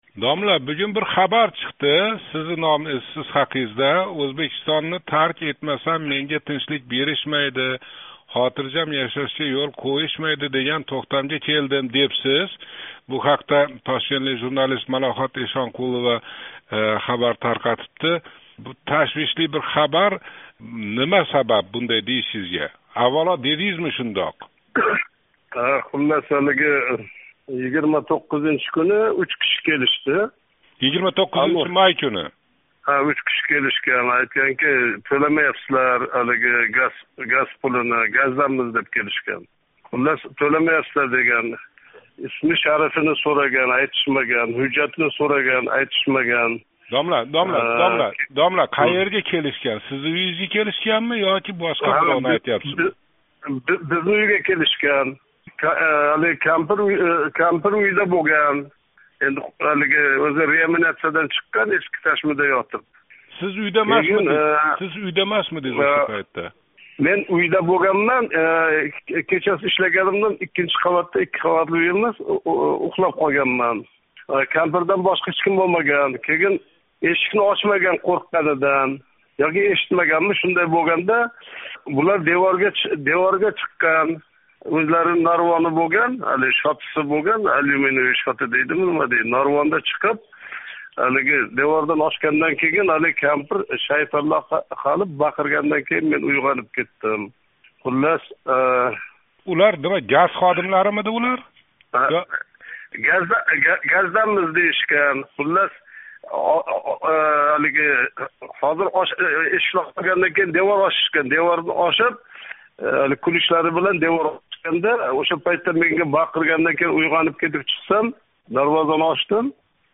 Озодлик мухбири адиб билан боғланиб, ундан бундай кескин қарорга келиши сабабларини сўради.